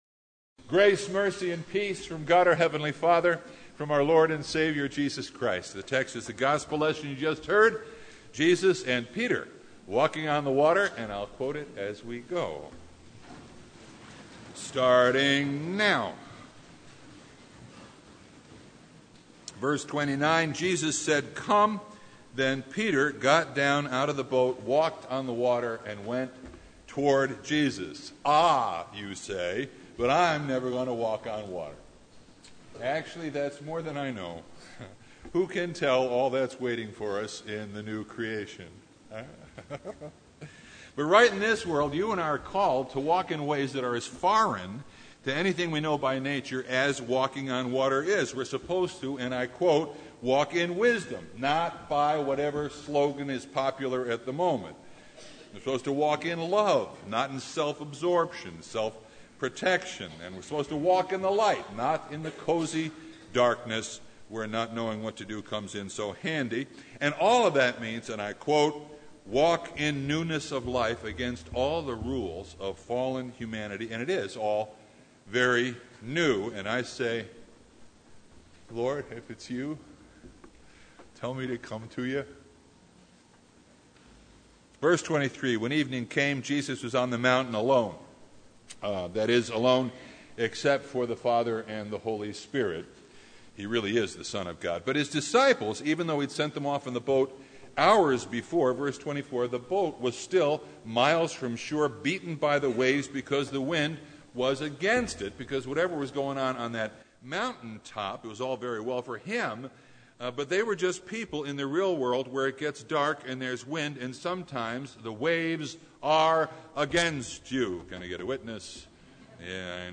Matthew 14:22-33 Service Type: Sunday Jesus and Peter walking on the water…
Sermon Only